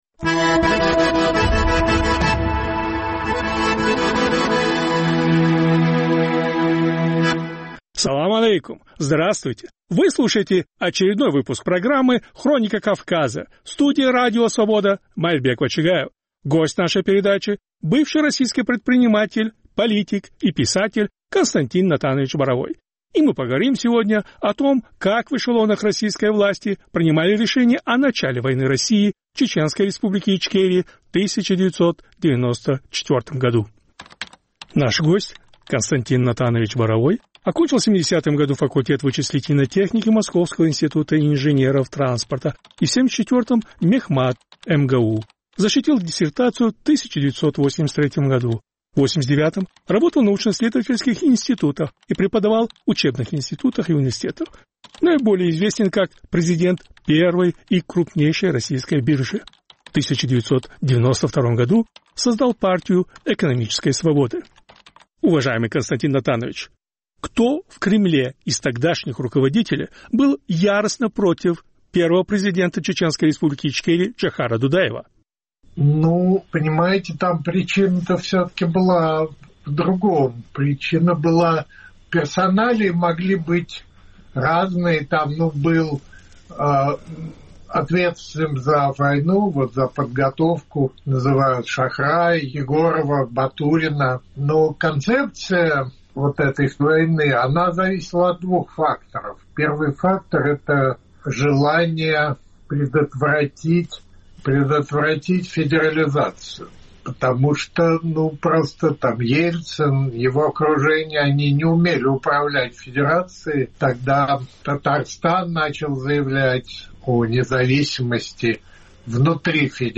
Первая война в Чечне, Джохар Дудаев и Кремль: интервью с Константином Боровым
Была ли нефть причиной войны; как в Кремле принимали решение о начале войны в Чечне и почему им категорически не нравился президент ЧРИ Джохар Дудаев. Эти и другие вопросы обсуждаем с политиком и предпринимателем Константином Боровым. Повтор эфира от 20 октября 2024 года.